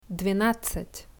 [dvee-nát-sat]